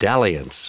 Prononciation du mot dalliance en anglais (fichier audio)
Prononciation du mot : dalliance